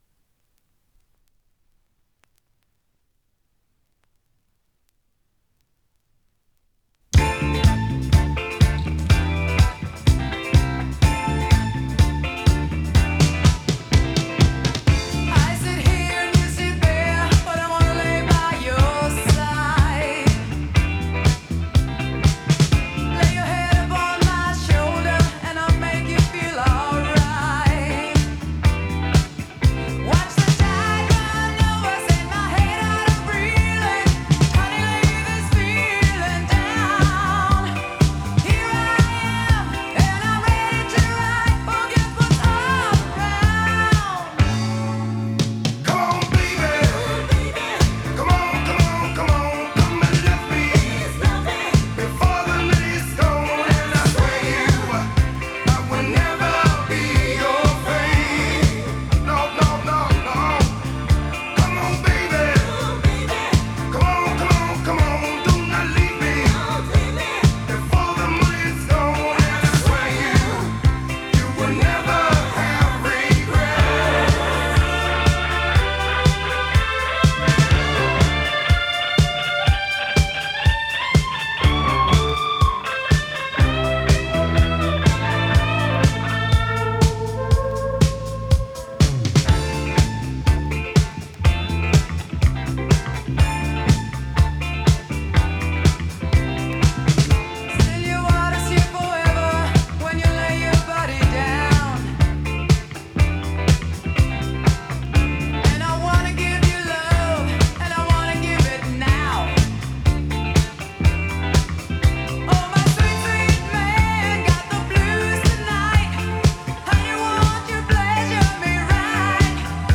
类型：Funk / Soul、Pop、Rock、Disco